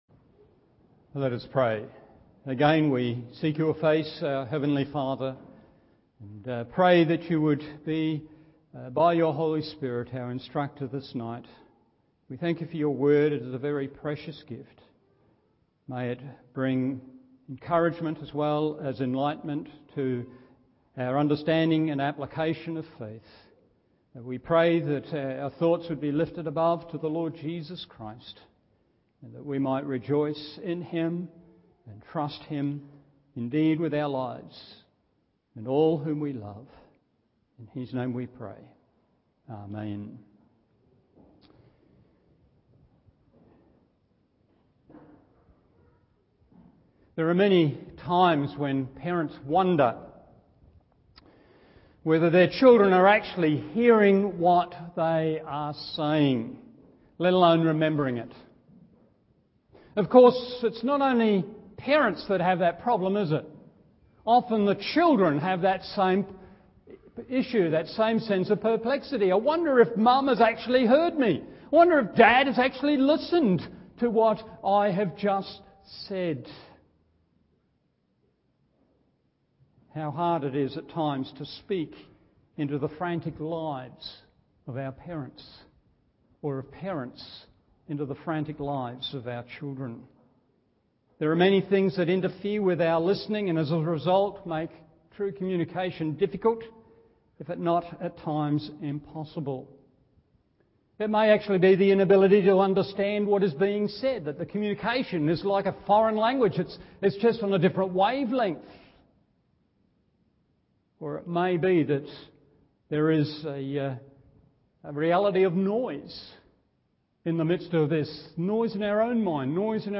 Evening Service Romans 4:11 1. A Sign 2. A Seal 3.